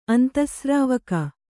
♪ antasrāvaka